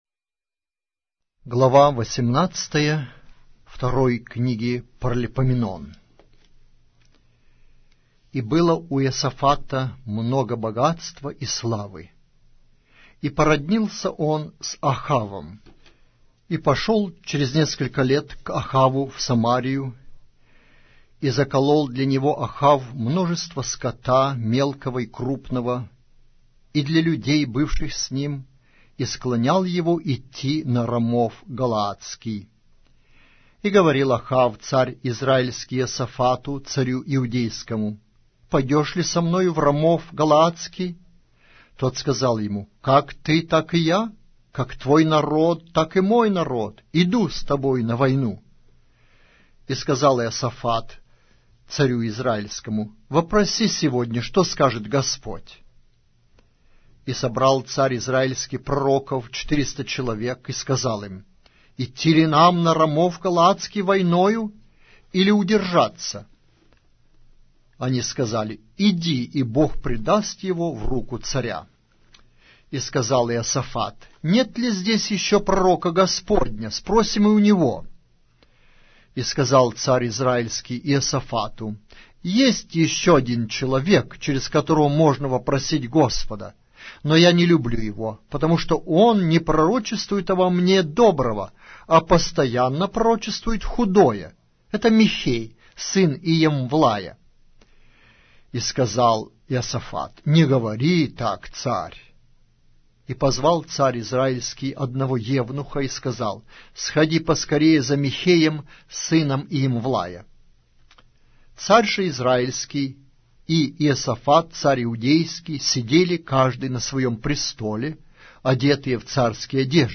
Аудиокнига: 2-я Книга. Паралипоменон